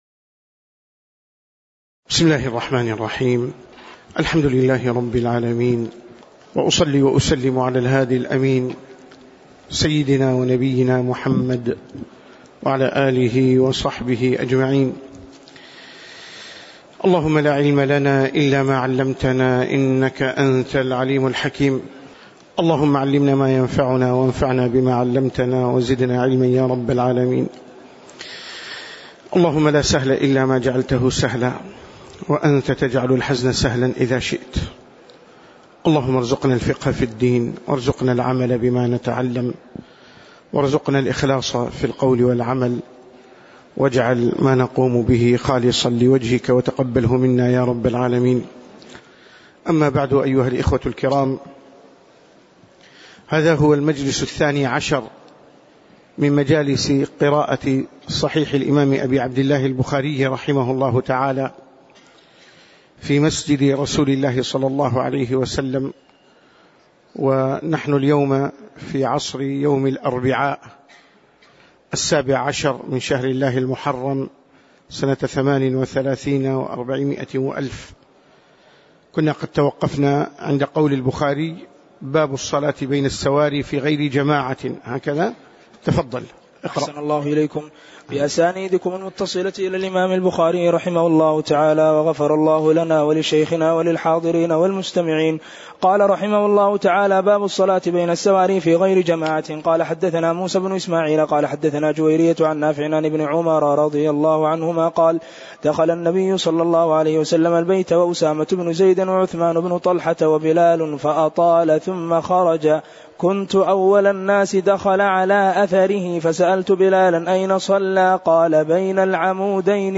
تاريخ النشر ١٧ محرم ١٤٣٨ هـ المكان: المسجد النبوي الشيخ